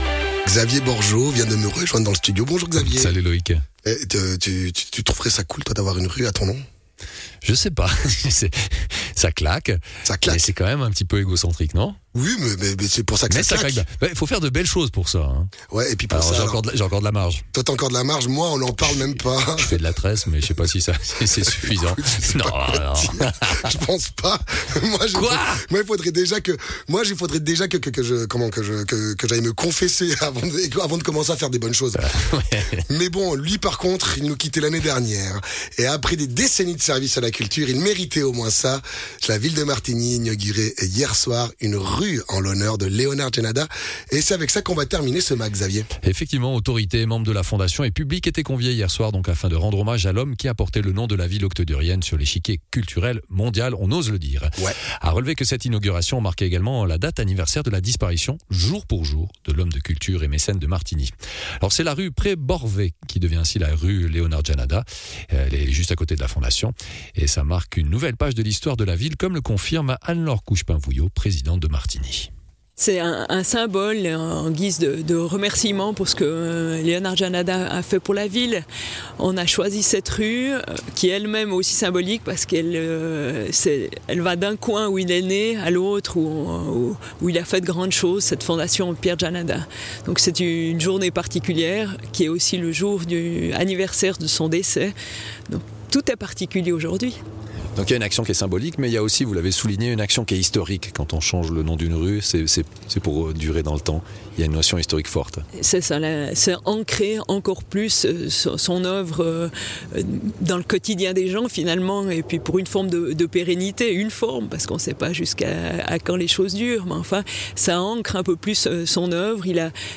Intervenant(e) : Anne-Laure Couchepin Vouilloz, Présidente de la ville de Martigny